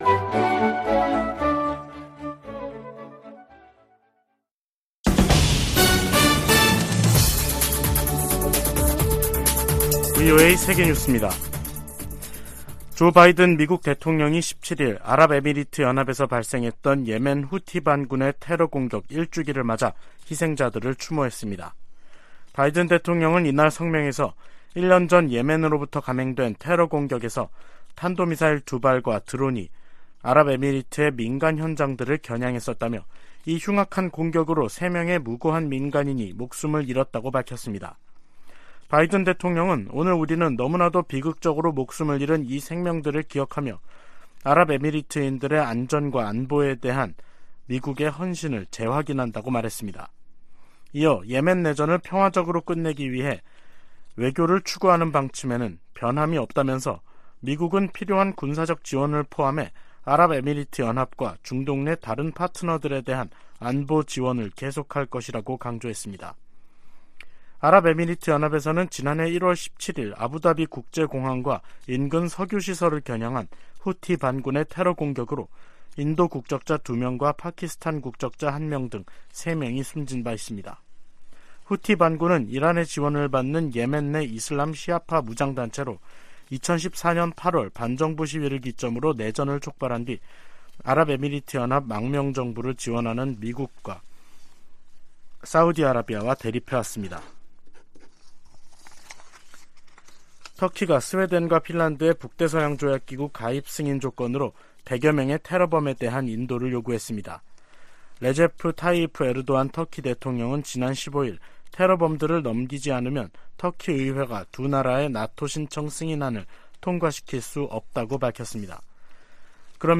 VOA 한국어 간판 뉴스 프로그램 '뉴스 투데이', 2023년 1월 17일 3부 방송입니다. 미국 해군참모총장은 한국의 ‘자체 핵무장’ 안과 관련해 미국의 확장억제 강화를 현실적 대안으로 제시했습니다. 유엔은 핵보유국 의지를 재확인한 북한에 긴장 완화를 촉구하고 유엔 결의 이행과 외교를 북핵 문제의 해법으로 거듭 제시했습니다.